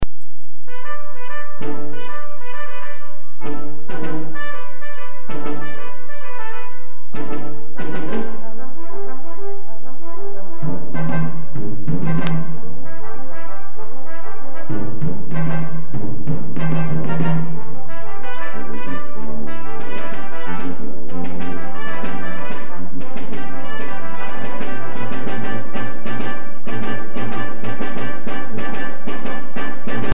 for Brass Quintet and Percussion